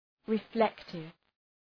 Προφορά
{rı’flektıv}